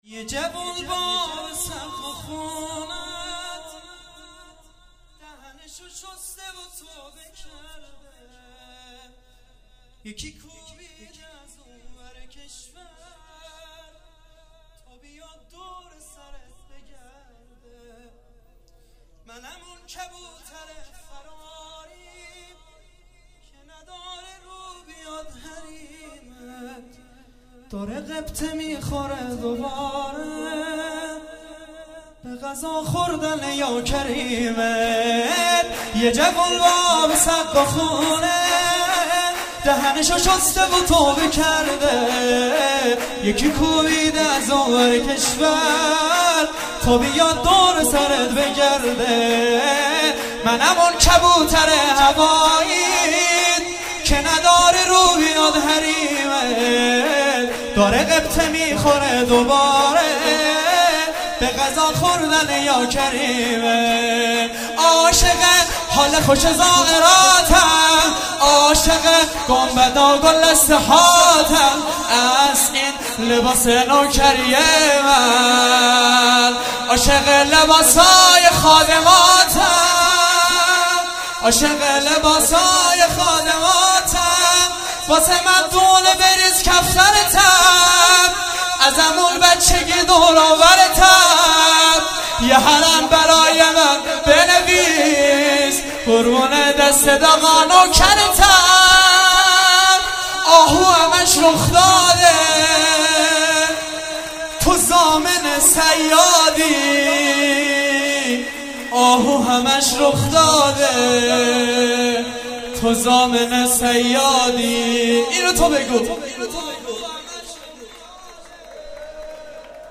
چهاراه شهید شیرودی حسینیه حضرت زینب (سلام الله علیها)
شور